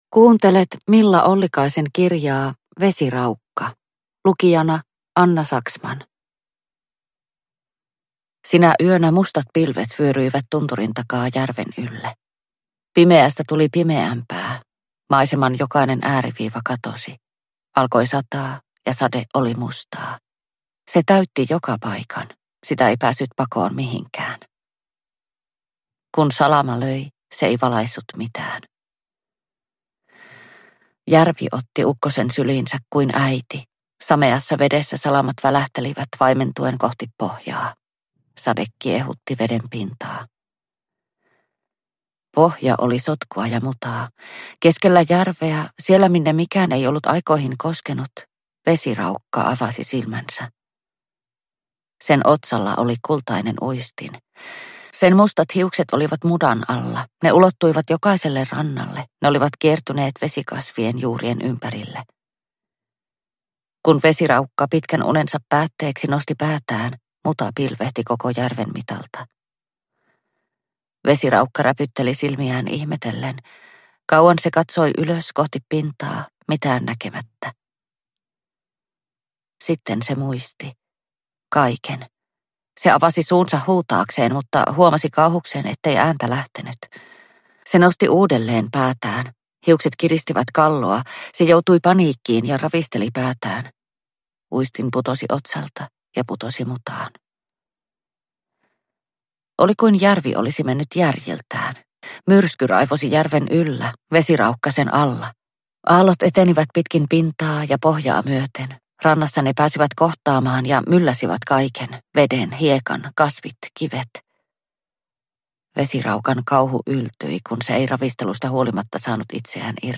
Vesiraukka – Ljudbok – Laddas ner